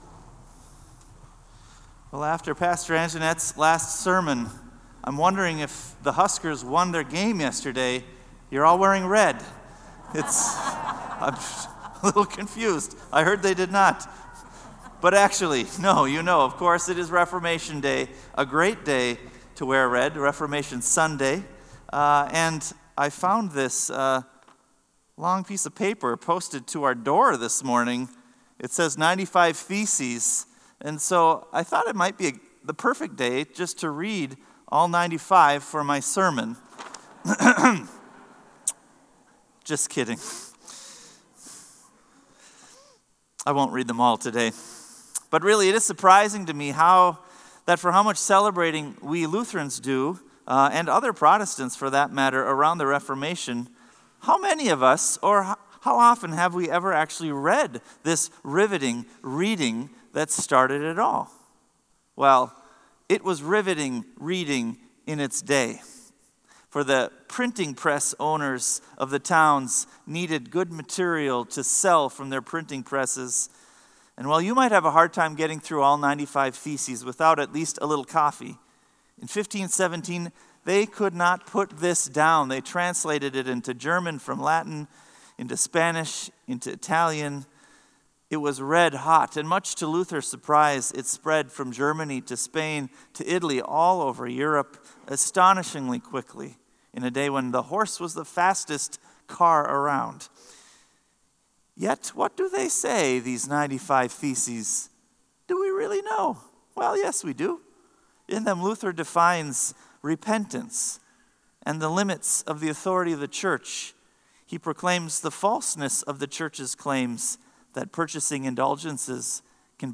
Sermon “But Now …”